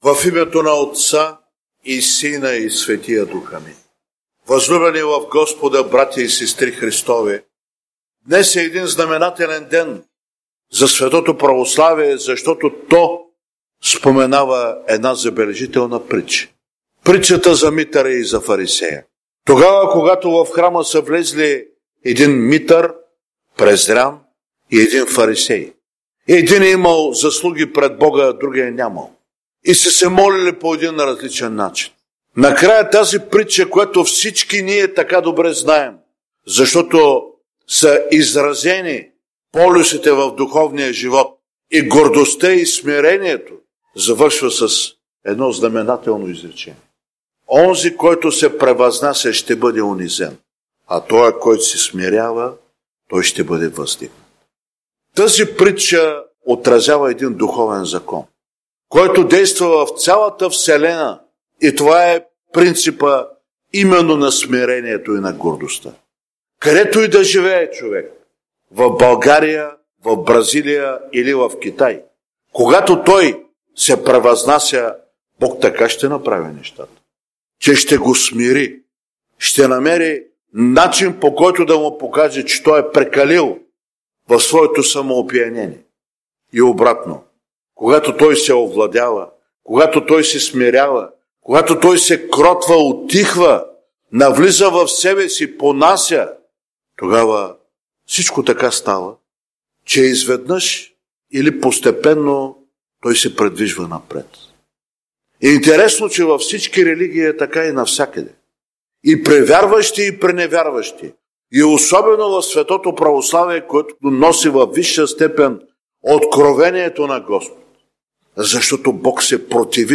Изслушайте цялата неделна проповед тук: